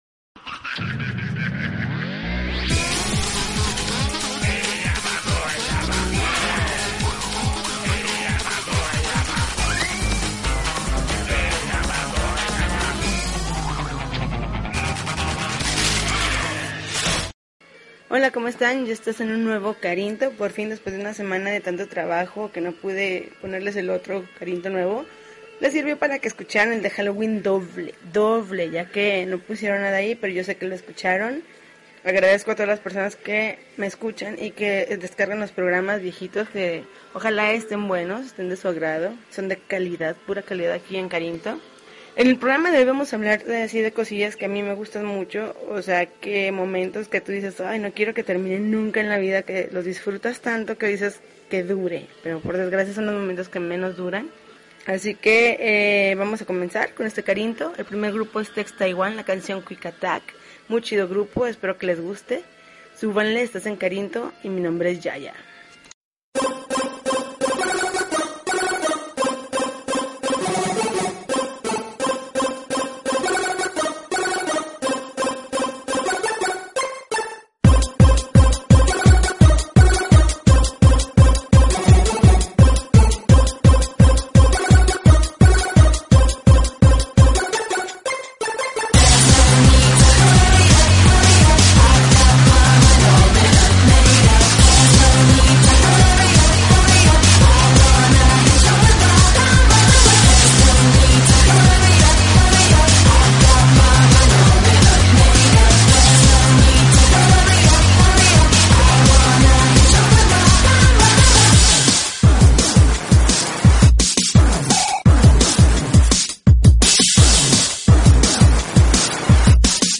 November 14, 2012Podcast, Punk Rock Alternativo